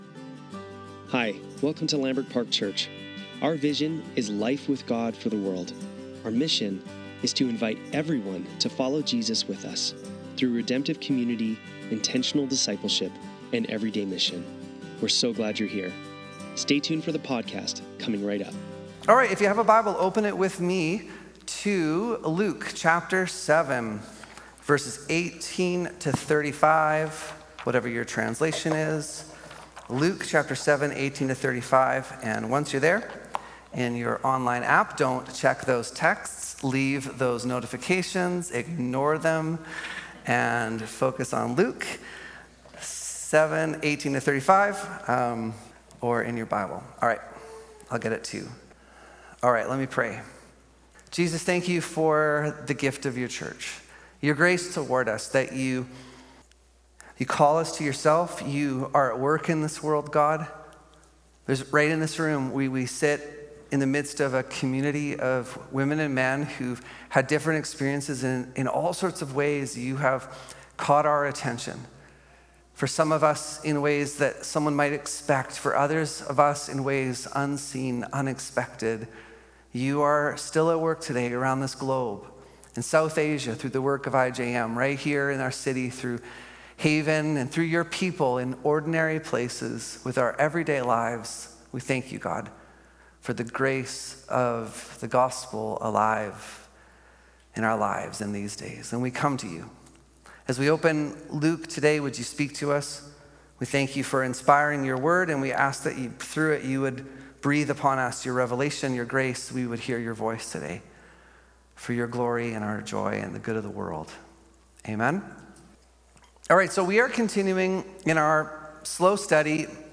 Sunday Service - May 18, 2025